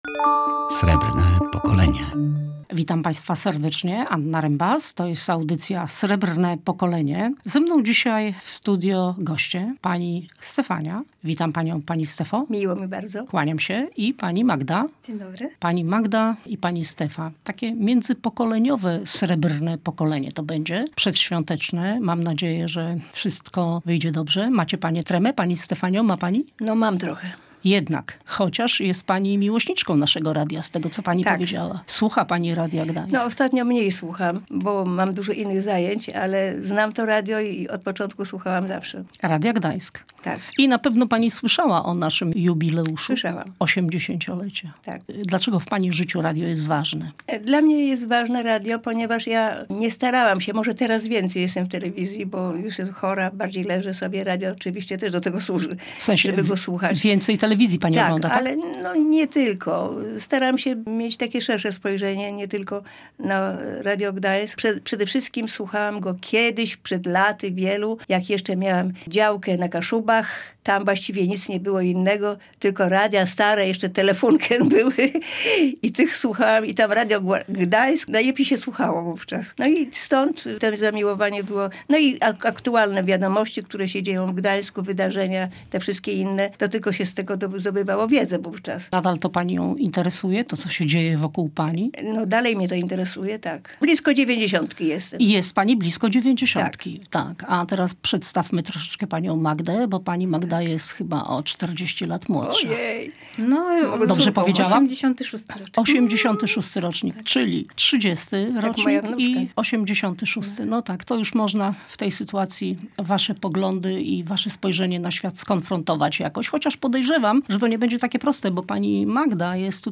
W audycji dla seniorów gościliśmy dwa pokolenia, nawiązując tym do jubileuszowego logo z okazji 80-lecia Radia Gdańsk. Jak seniorzy patrzą na młodych, a jak młodzi na seniorów? Czego generacje uczą się od siebie?